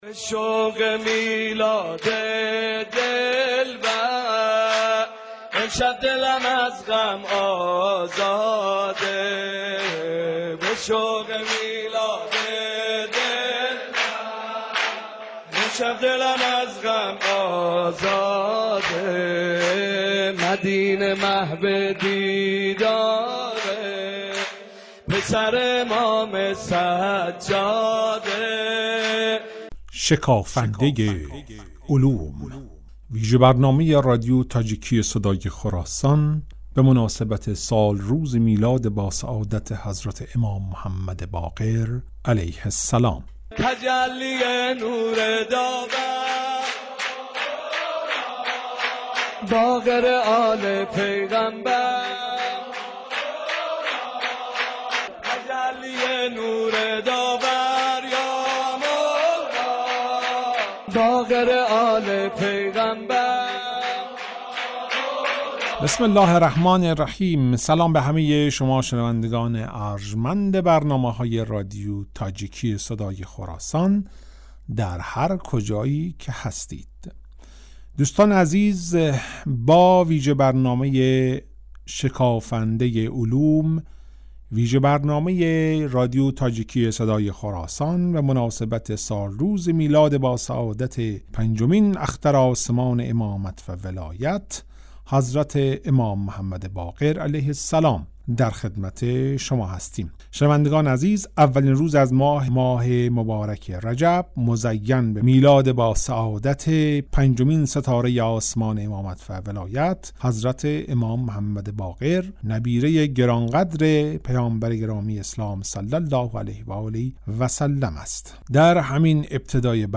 “Шикофандаи улум”-вижабарномаи валодати Имом Боқир(а),набераи пок Паёмбари гиромии ислом аст,ки дар радиои тоҷикӣ таҳия шуда аст.